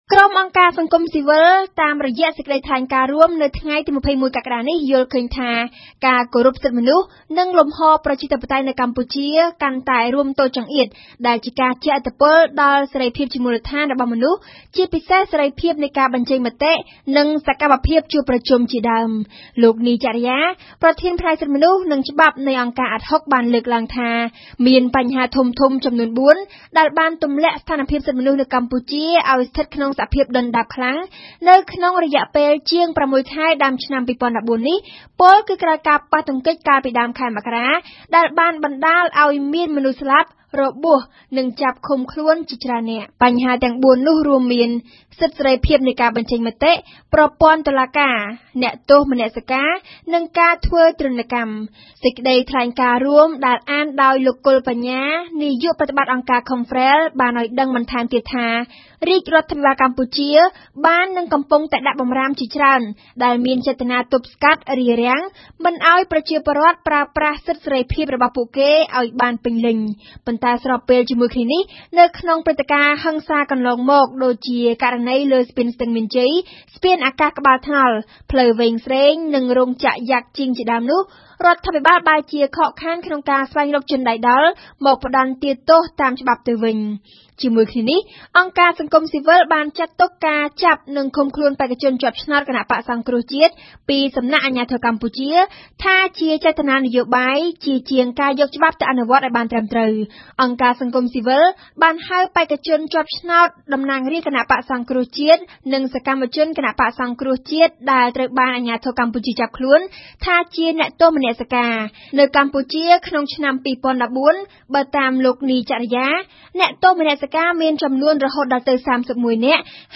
តំណាងអង្គការសង្គមស៊ីវិល ថ្លែងការណ៍រួមគ្នានៅក្នុងសន្និសីទកាសែត ថ្ងៃទី២១កក្កដា ឆ្នាំ២០១៤ នៅសណ្ឋាគារសាន់វ៉េ